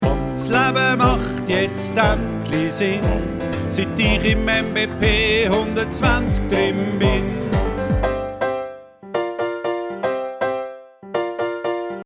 Der Klingelton zum Modul